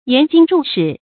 研经铸史 yán jīng zhù shǐ
研经铸史发音